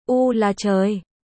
ベトナム語発音
ベトナム語で「驚いた」という表現その③：U là Trời(ウーラチョーイ)